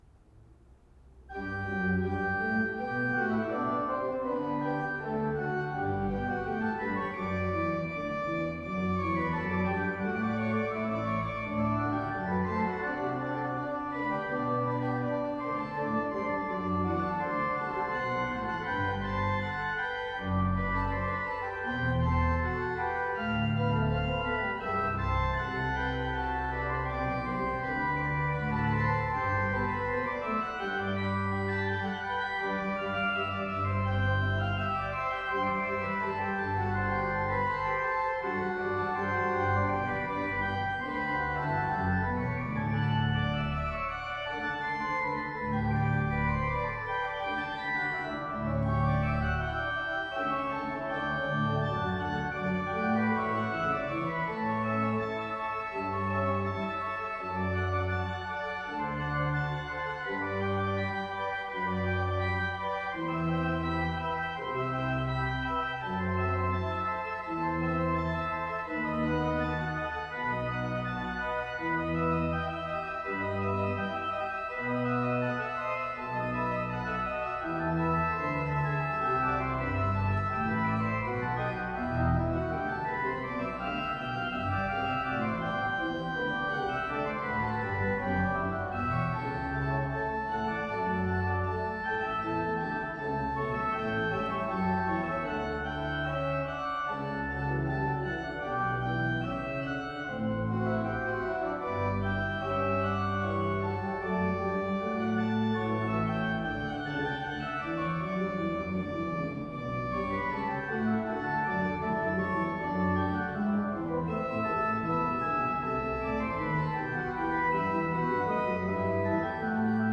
Recordings of the Organ